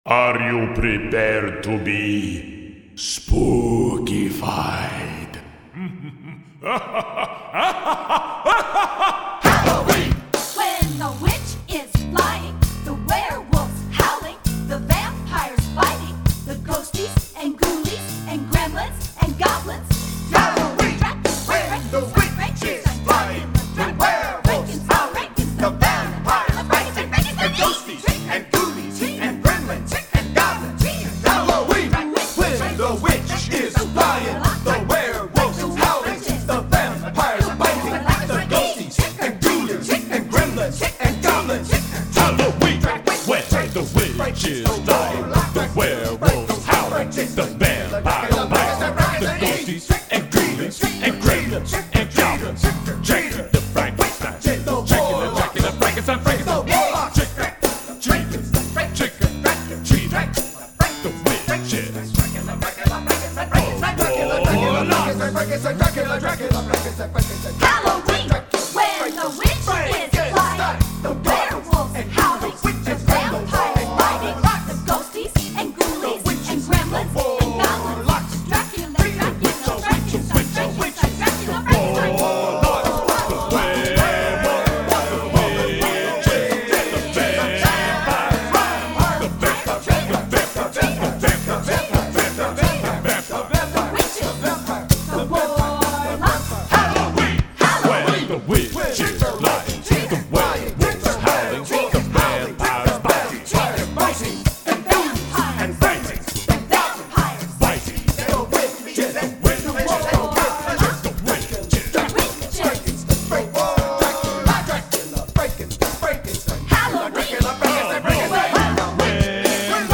Voicing: SATB and Percussion